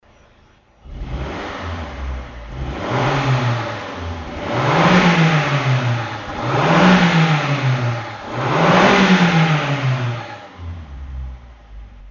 Stainless Steel Exhaust Muffler
[死气喉声效 Muffler Sound]
AutoExe_Mazda_CX7_ER_MER8500_Audio.mp3